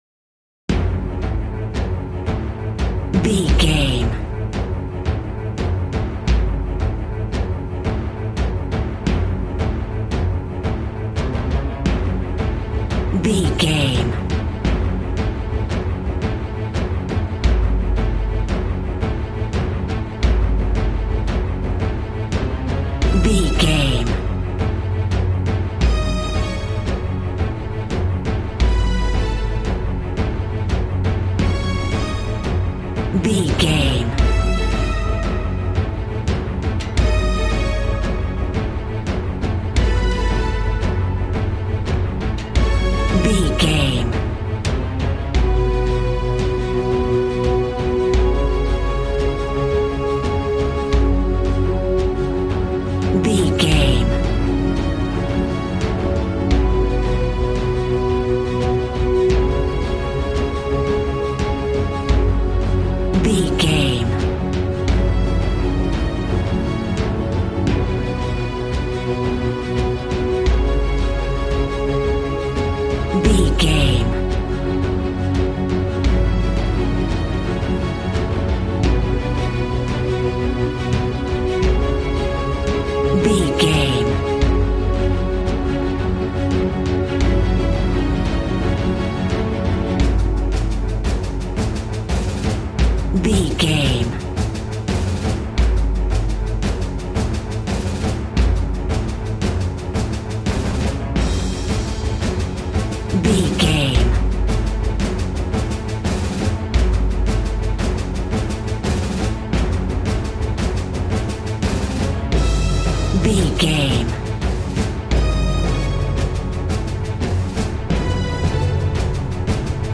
Epic / Action
Fast paced
In-crescendo
Thriller
Uplifting
Aeolian/Minor
G♭
dramatic
powerful
strings
brass
percussion
synthesiser